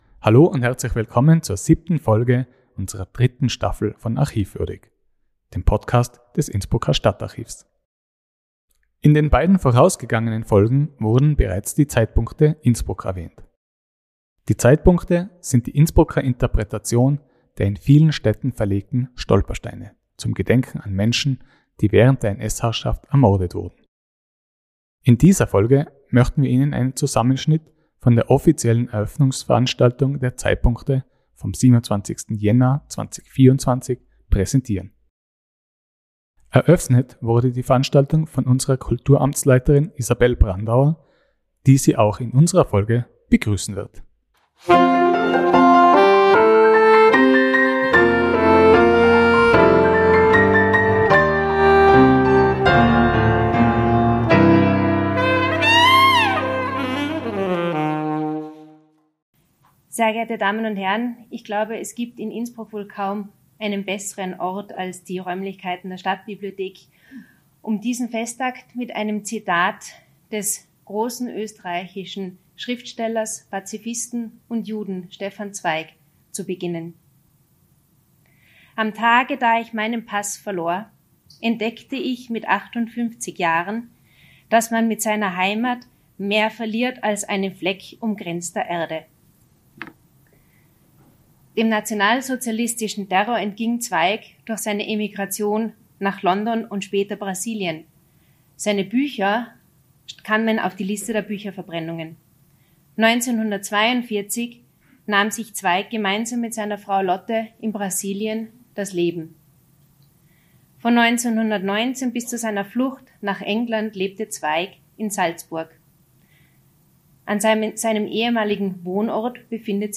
Ausschnitte von der Eröffnungsfeier der Zeitpunkte vom Jänner 2024.